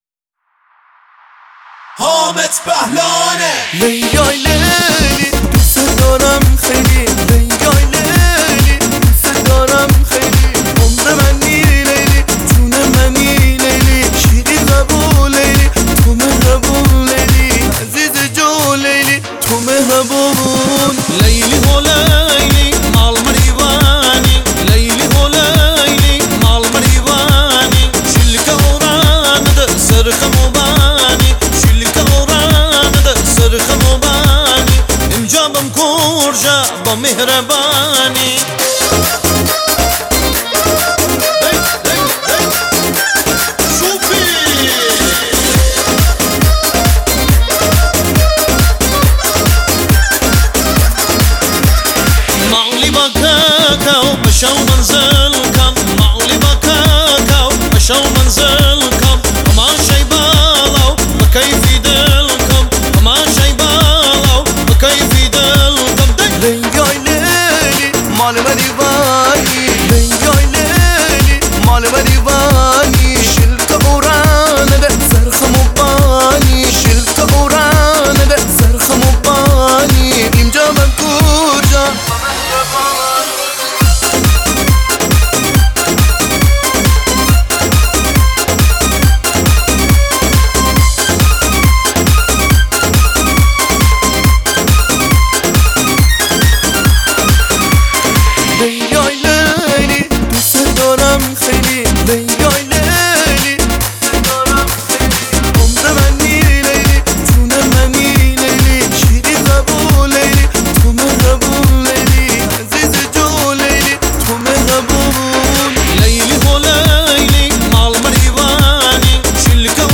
ریمیکس عروسی شاد